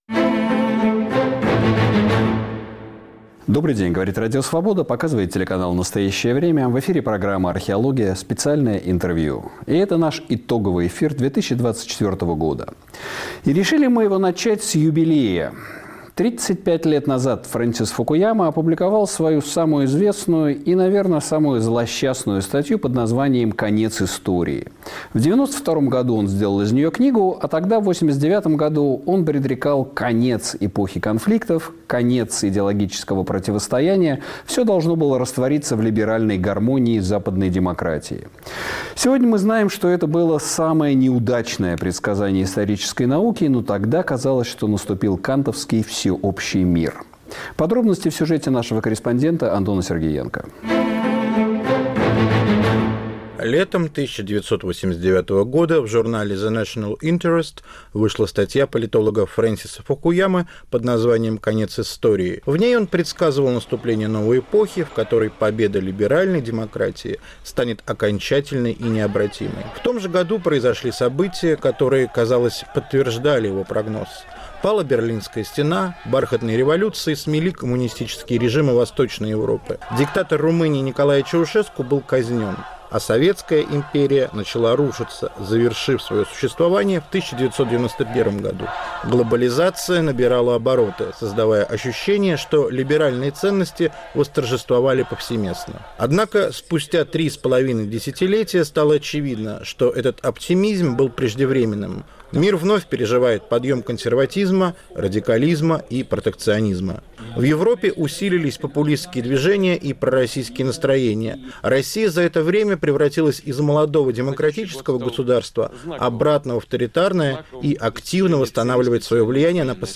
На вопросы Сергея Медведева отвечает политолог Владимир Пастухов